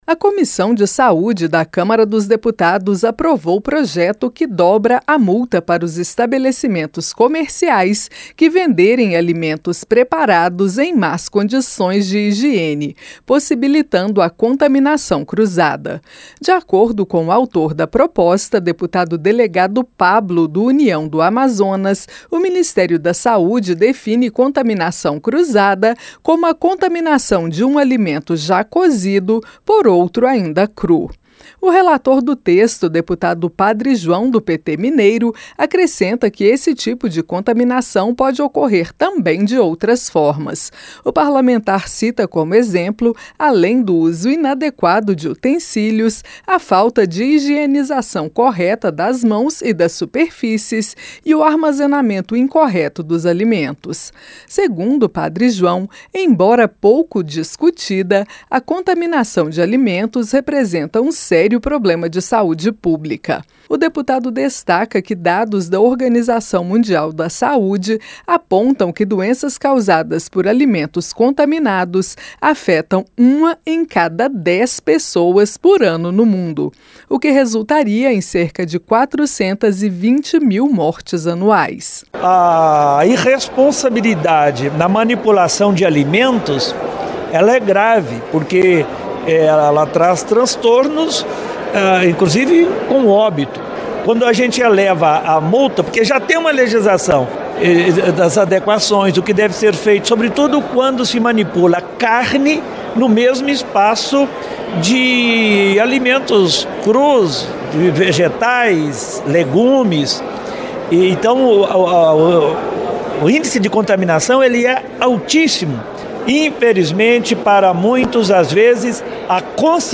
COMISSÃO APROVA PROJETO QUE PREVÊ PENA MAIOR PARA CONTAMINAÇÃO CRUZADA DE ALIMENTOS. A REPÓRTER